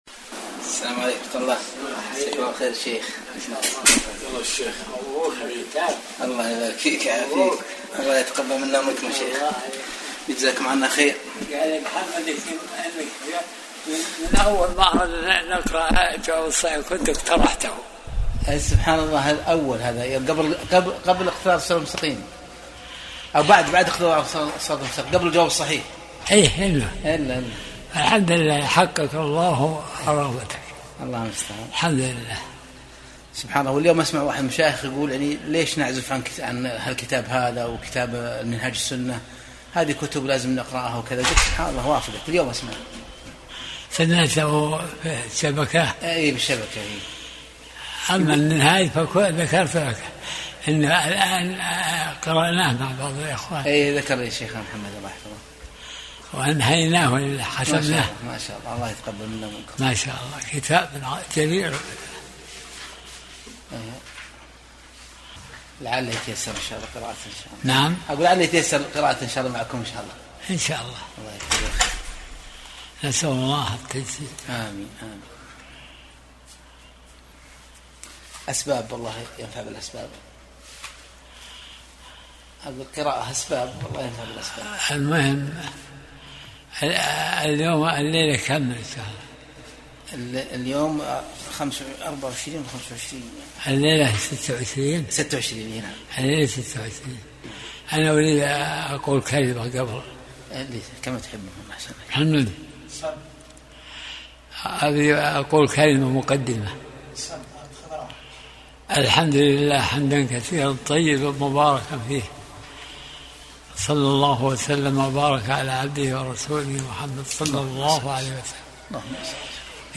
درس الأربعاء 59